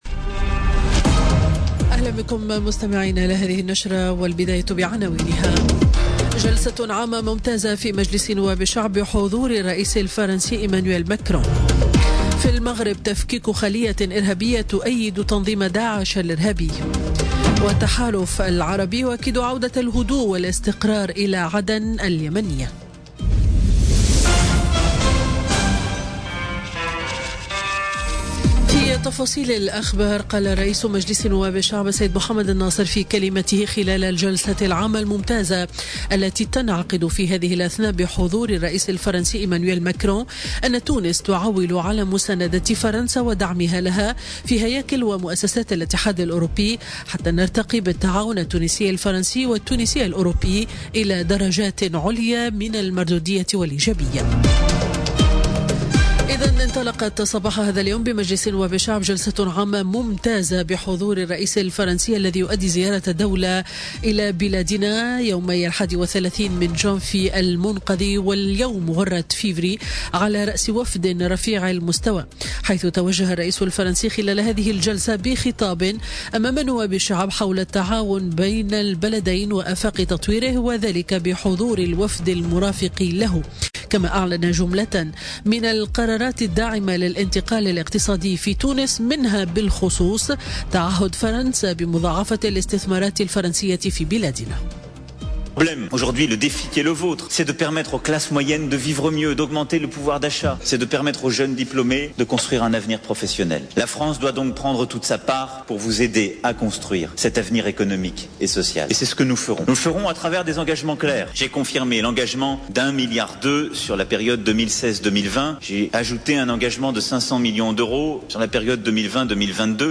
Discours du président français Emmanuel Macron à l'ARP